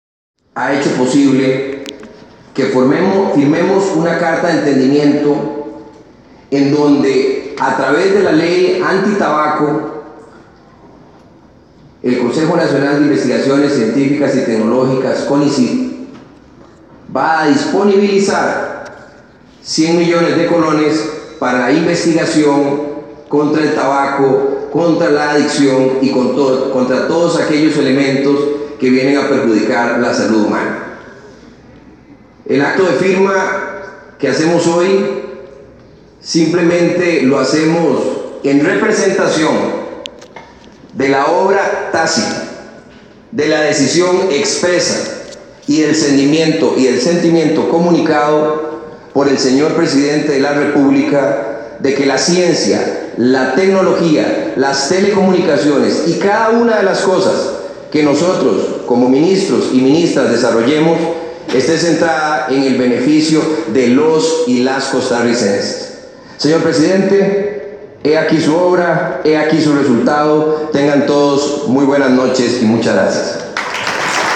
Discurso del ministro Luis Adrián Salazar sobre carta de entendimiento para financiar investigación en salud y problemas del tabaco